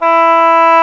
Index of /90_sSampleCDs/Roland LCDP04 Orchestral Winds/WND_Bassoons/WND_Bassoon 3
WND F#3 DB.wav